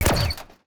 UIClick_Menu Laser Hit Rustle Tail 03.wav